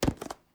Objects, Container, Plastic Lunch Box, Squeaky Plastic, Handle, Grab 03 SND114930 S07.wav